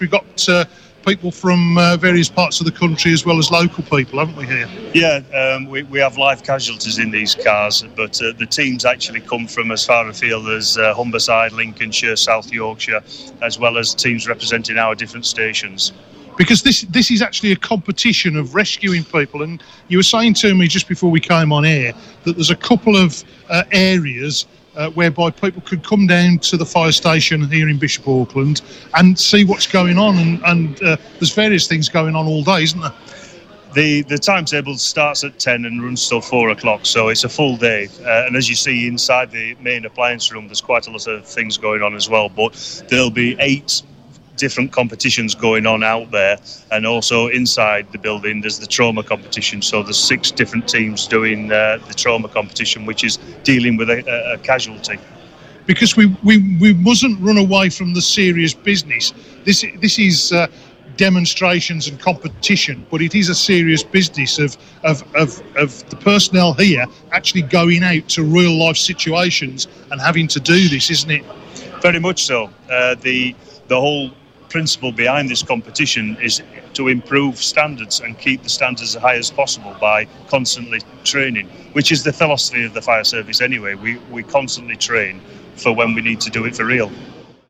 Open day and extrication demonstrations at Bishop Auckland fire station